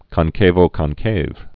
(kŏn-kāvō-kŏn-kāv)